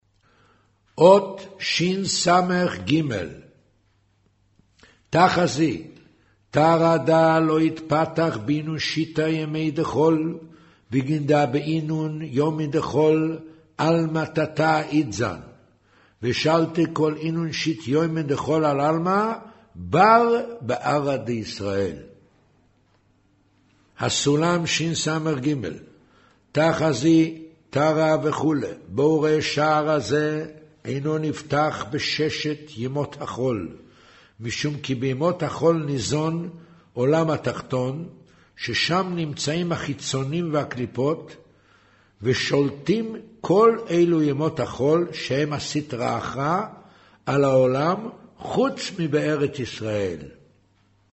קריינות זהר